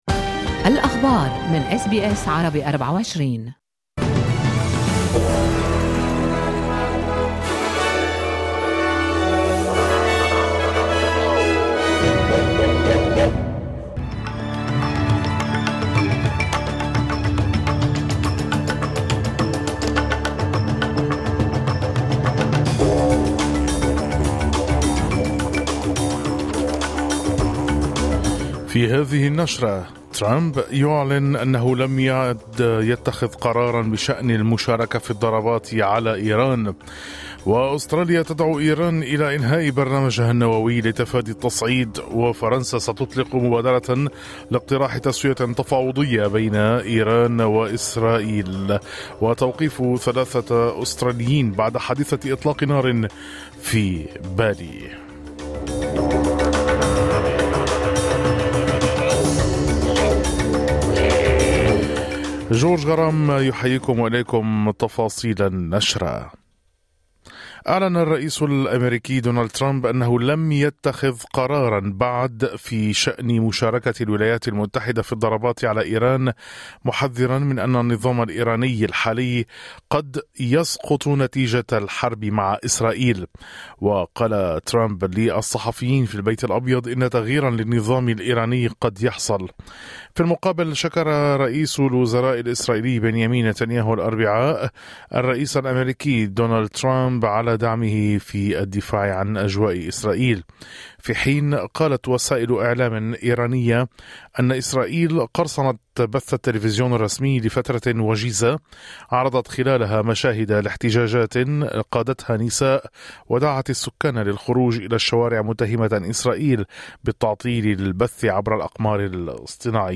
نشرة أخبار الصباح 19/06/2025